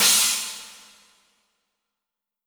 Crashes & Cymbals
Metro Crash 3.wav